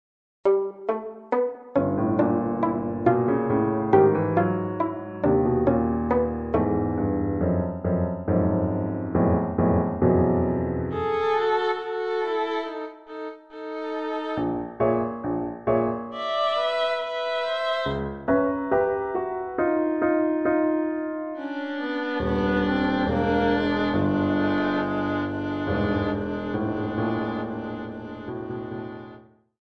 Besetzung Violine und Klavier